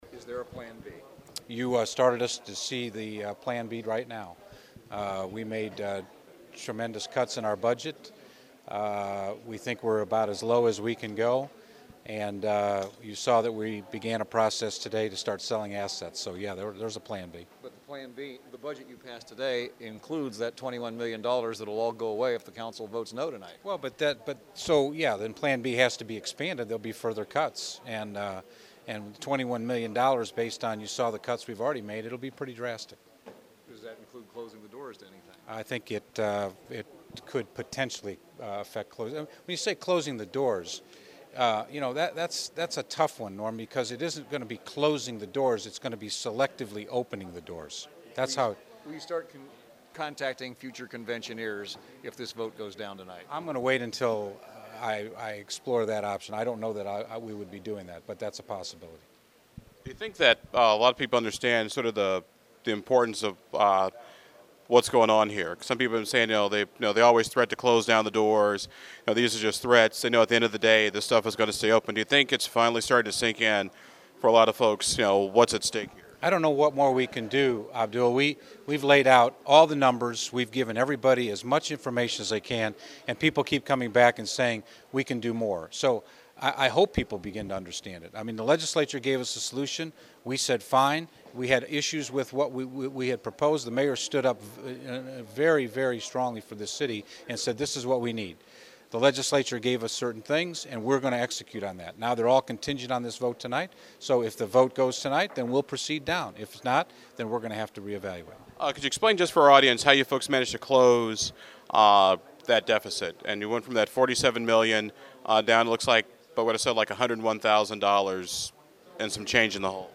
Below is some audio from today’s meeting…